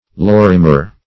Lorimer \Lor"i*mer\